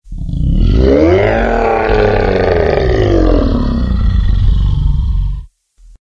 death.ogg